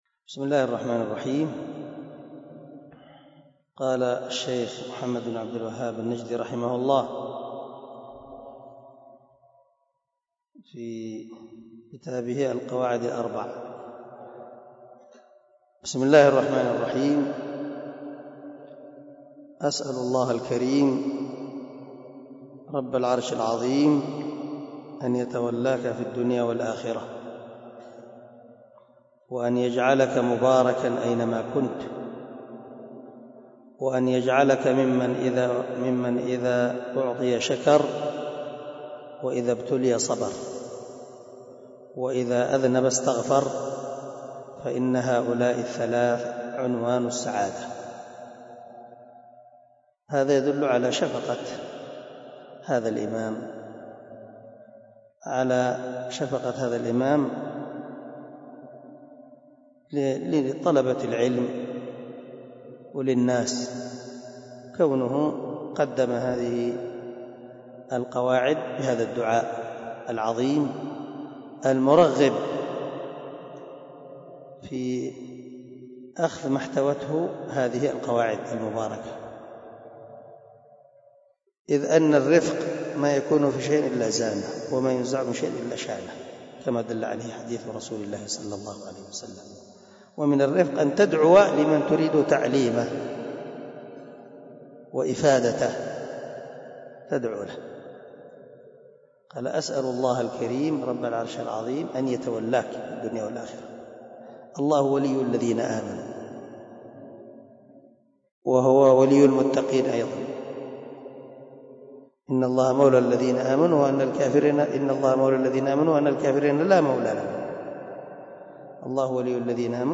الدرس 1 من شرح القواعد الأربع
دار الحديث- المَحاوِلة- الصبيحة.